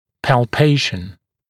[pæl’peɪʃn][пэл’пэйшн]пальпация